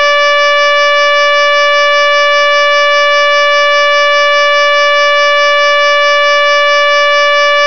Provides up to 85 dB at 5 feet.